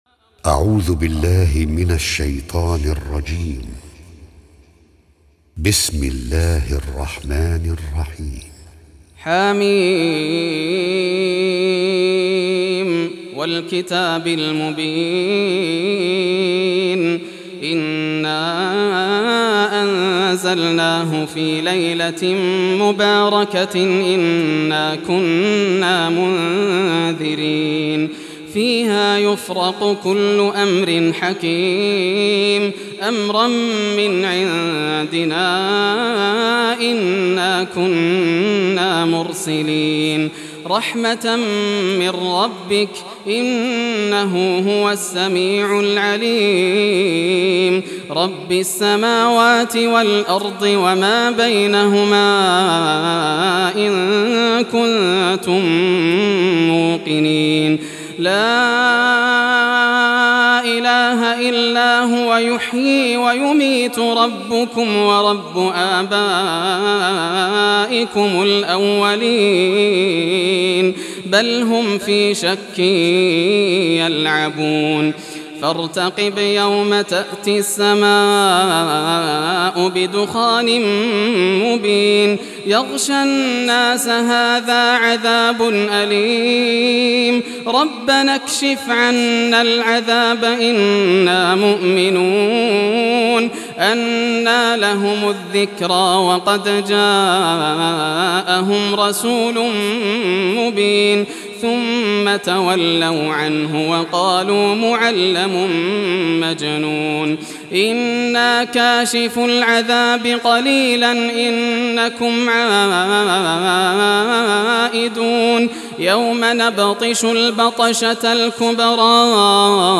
إصدار نسيم السحر 1429هـ > الإصدارات > المزيد - تلاوات ياسر الدوسري